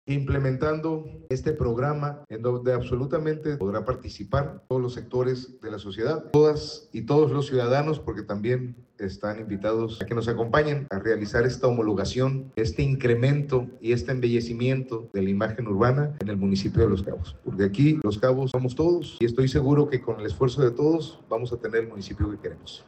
El arranque del programa se realizó al interior del palacio municipal, donde el alcalde Christian Agúndez destacó que este plan surge de la coordinación entre el Ayuntamiento de Los Cabos y el Gobierno del Estado, con una visión común enfocada en la homologación de la imagen urbana, el embellecimiento de espacios públicos y el fortalecimiento de proyectos de movilidad ya gestionados ante instancias federales, así como del trabajo colaborativo con distintos sectores de la sociedad.
presidente municipal de Los Cabos, Christian Agúndez Gómez